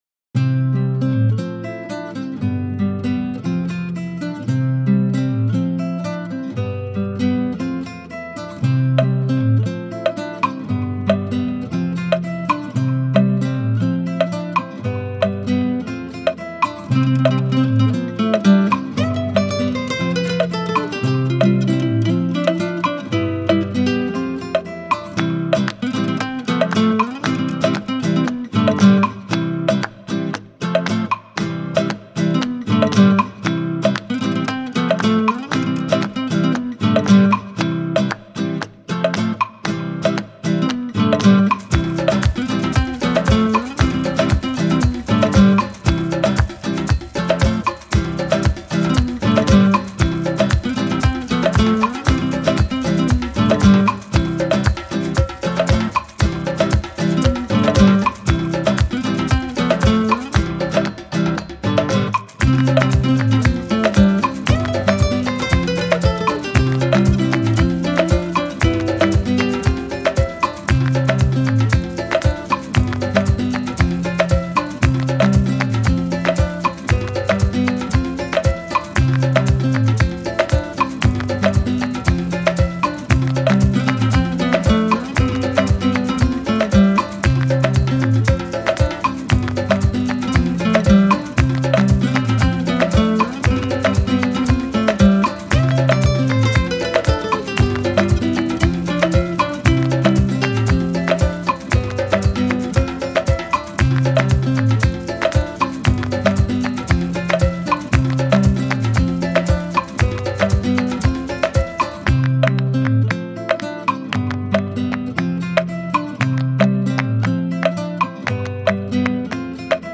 タグ: アフリカ、映画、希望に満ちた、ギター